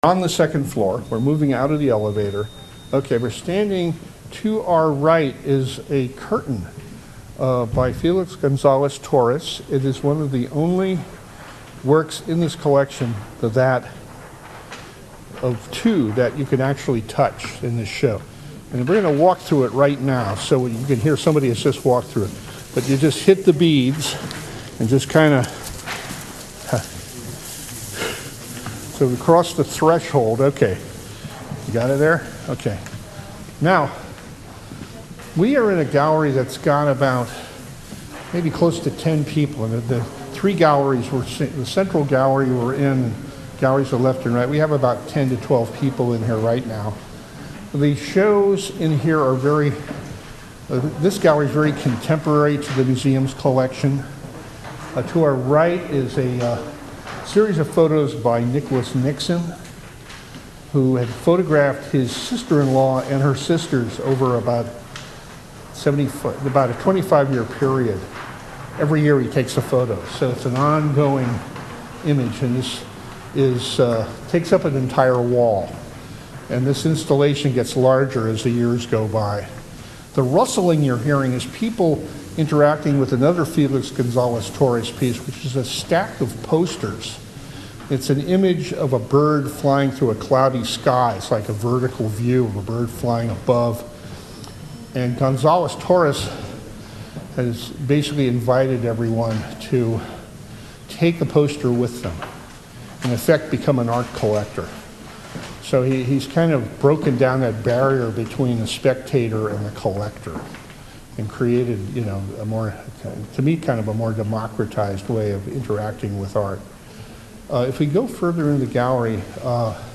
Blindfolded Docent Tour